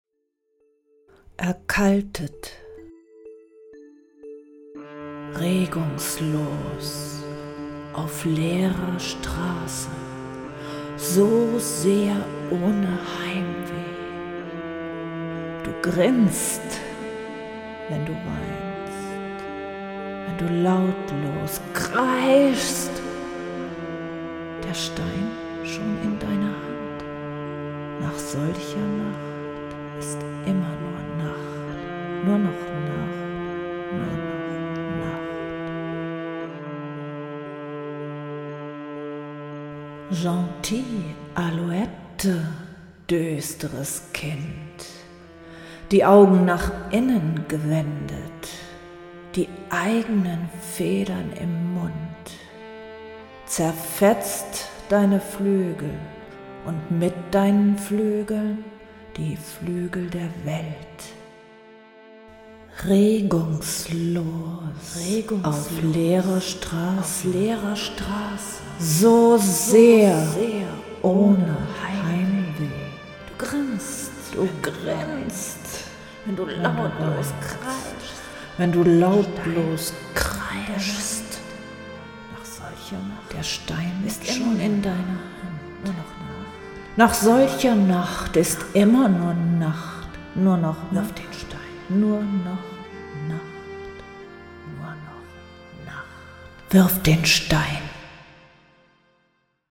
Zur Zeit mal wieder verstärkt im Tonstudio aktiv.